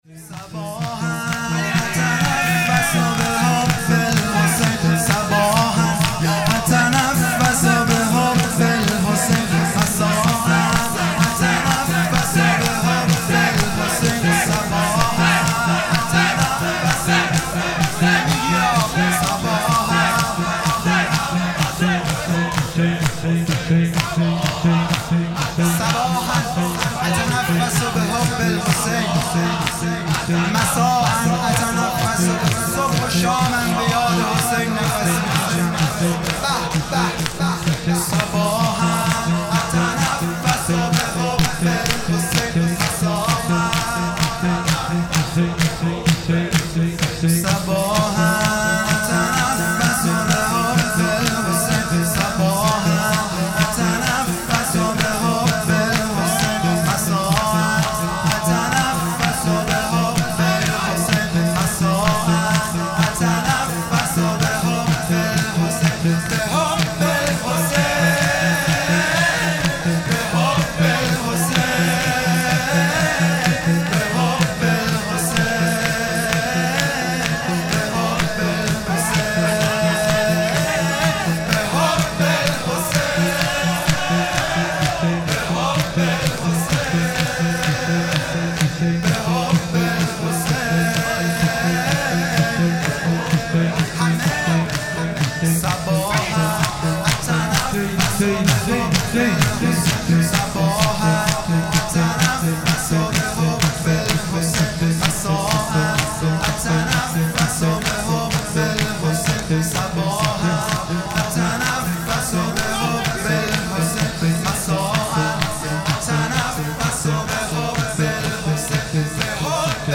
هیئت دانشجویی فاطمیون دانشگاه یزد
جلسه هفتگی